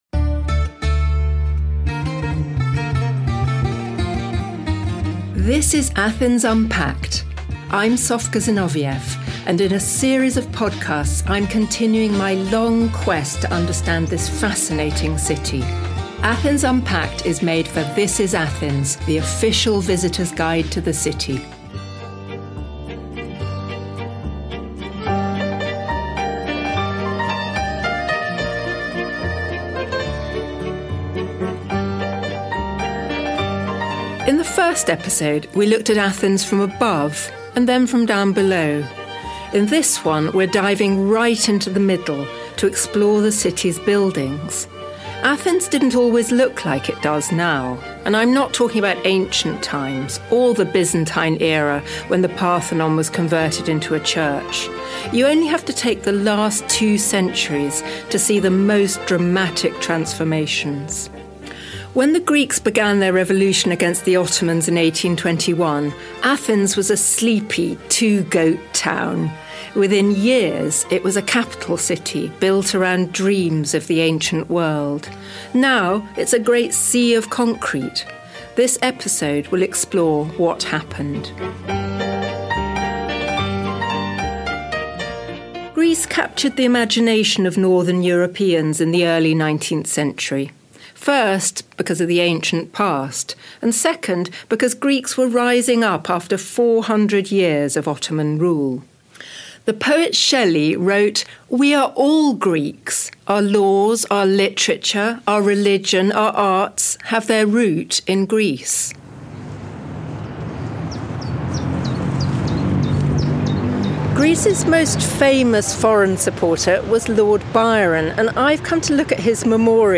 at the neoclassical Academy